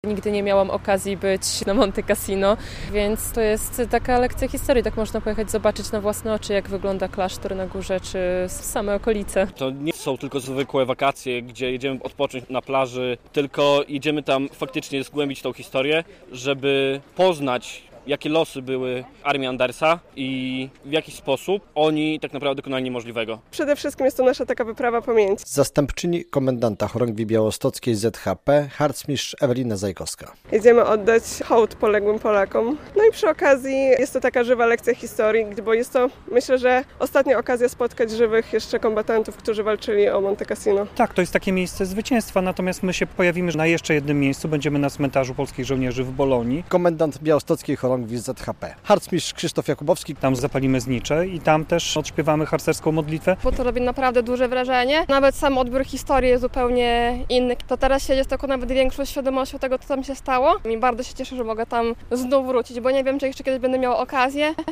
Harcerze ze Związku Harcerstwa Polskiego Chorągwi Białostockiej wyjechali do Włoch - relacja